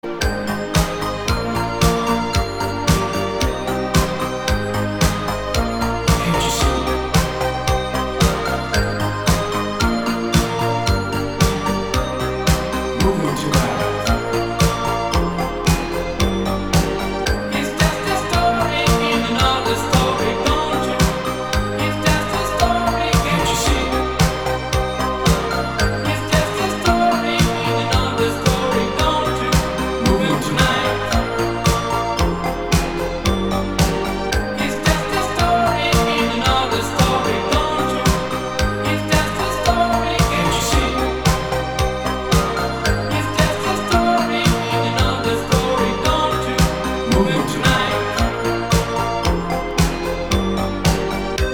поп
мужской вокал
диско
dance
электронная музыка
спокойные
дискотека 80-х
итало-диско